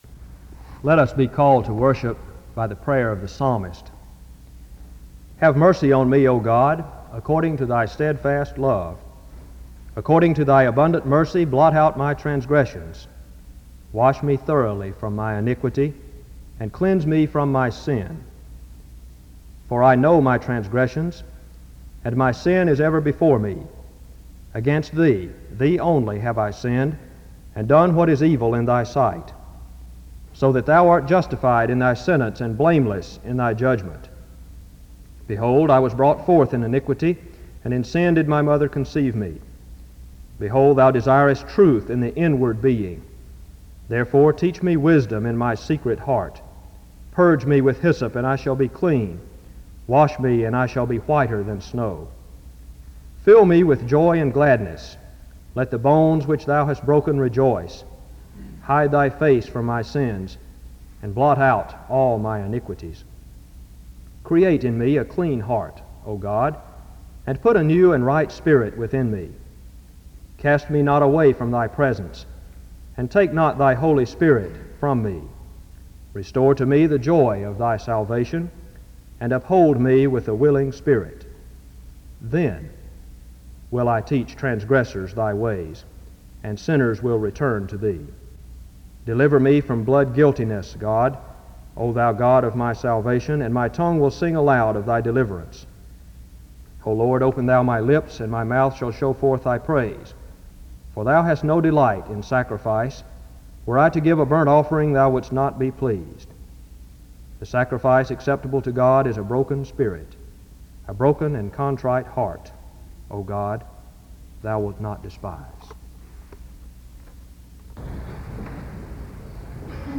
The service begins with a reading of Psalm 51 from 0:00-1:55.